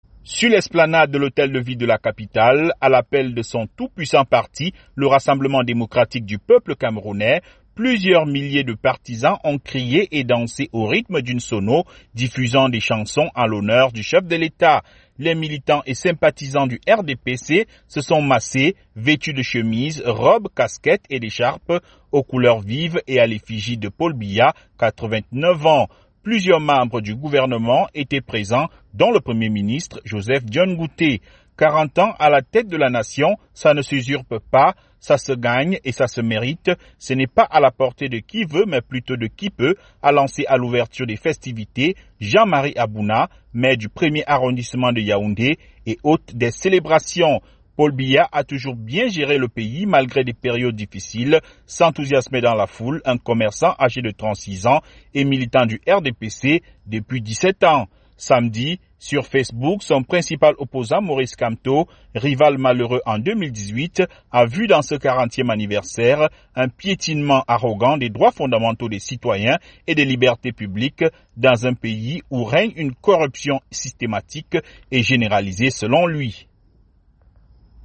Chants et célébrations pour marquer les 40 ans de Paul Biya au pouvoir
Plusieurs milliers de partisans du président camerounais Paul Biya ont célébré dimanche à Yaoundé, ses 40 ans à la tête de ce pays d'Afrique centrale.